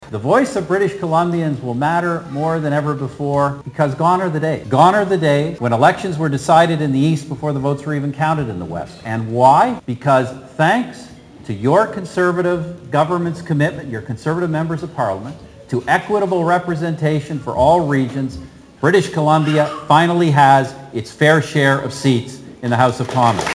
Stephen Harper speaks to supporters at private Black Creek function
The Conservatives have the leadership and proven experience to keep Canadians safe and our economy strong…That was conservative candidate Stephen Harper’s main message at the Coastal Black Winery while flanked by supporters.